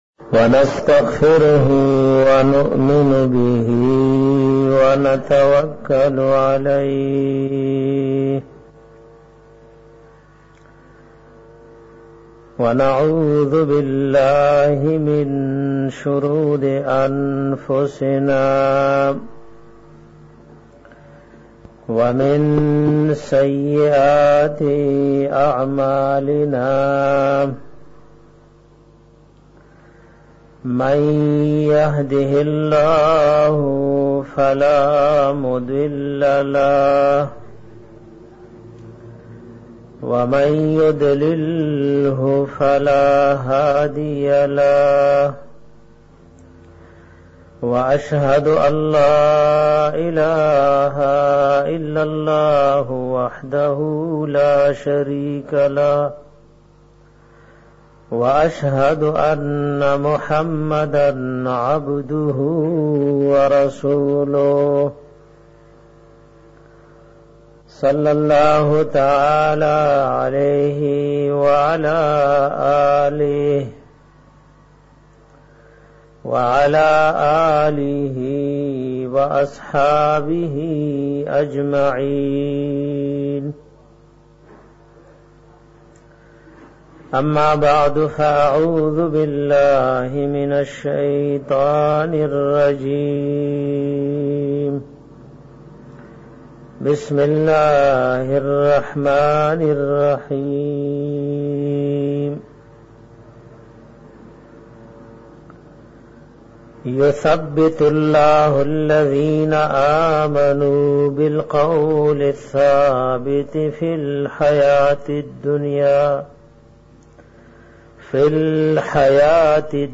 BAYAN PA BARA DA AZABI QABAR K PART 5